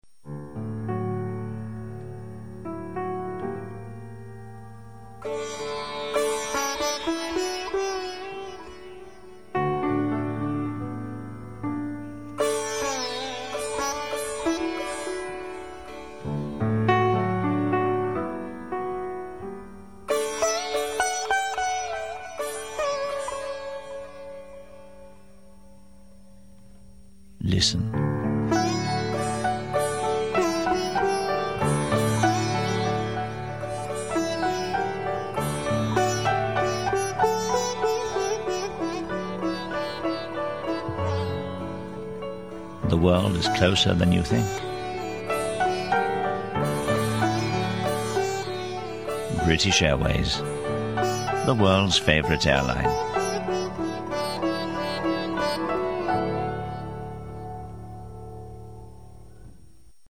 BA-Sitar-1.mp3